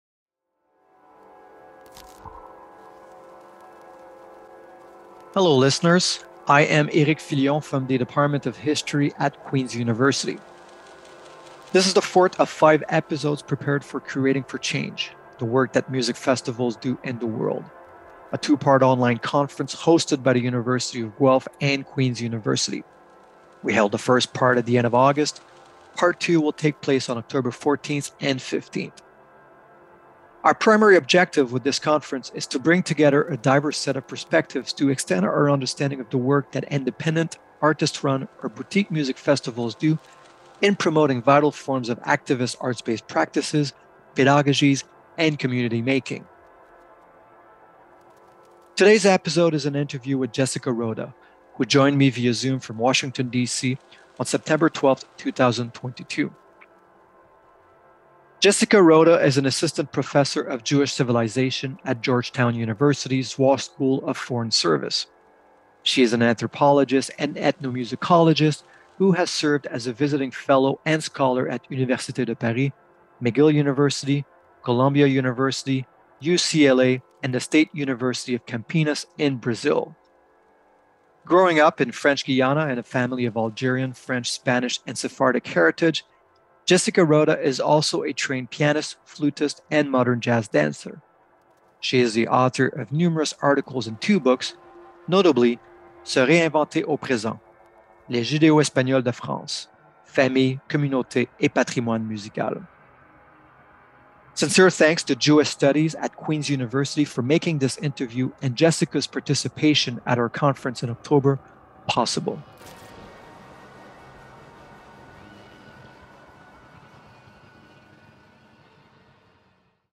Curating for Change: An Interview